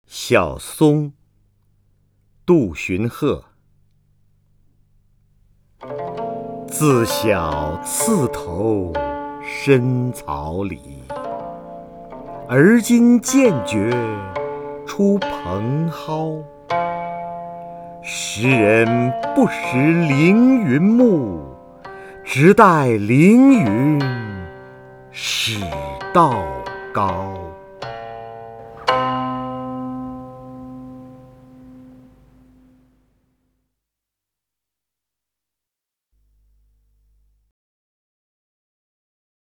瞿弦和朗诵：《小松》(（唐）杜荀鹤) （唐）杜荀鹤 名家朗诵欣赏瞿弦和 语文PLUS